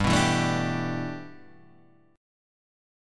G6add9 chord